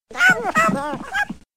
gremlins-mogwai.mp3